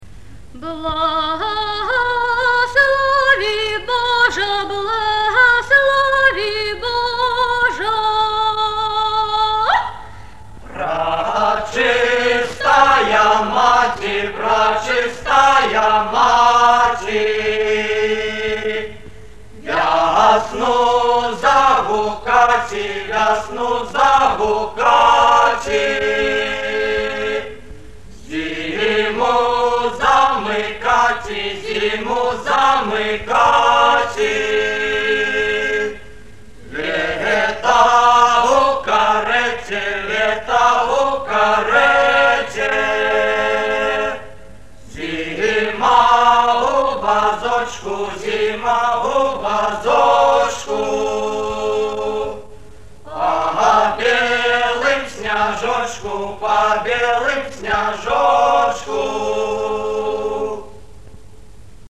Майстроўня сьпявае вясьнянку. Архіўны запіс.